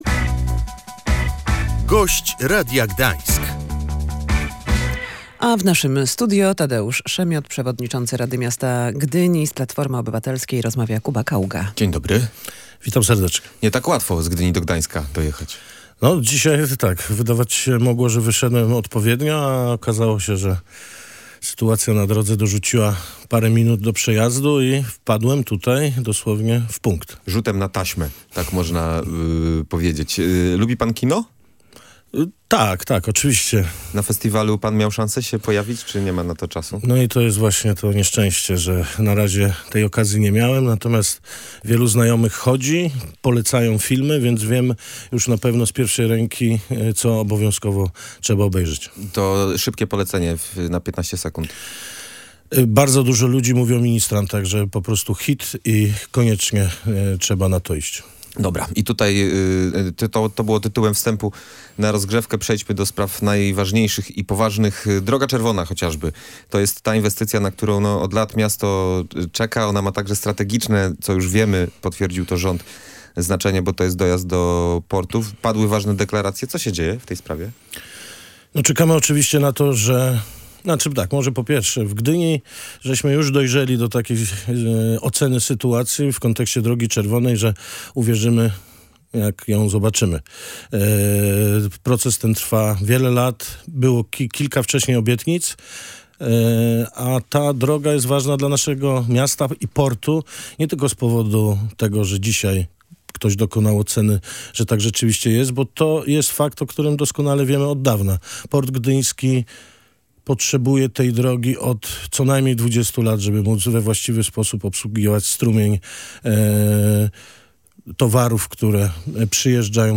Gdynia nie wyklucza wprowadzenia ograniczenia w nocnej sprzedaży alkoholu w mieście. Decyzja musi być jednak podjęta na podstawie rzetelnych danych – mówił w Radiu Gdańsk przewodniczący rady miasta Tadeusz Szemiot.